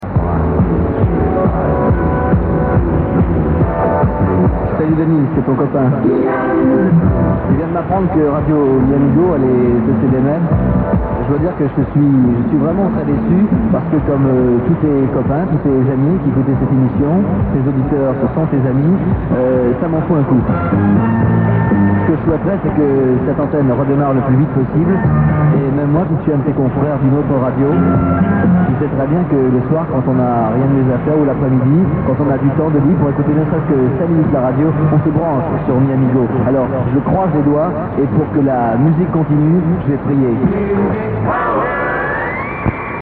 Yann Hegann animateur vedette d'Europe 1 et diffusé pendant l'une de ses émissions.